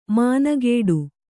♪ mānagēḍu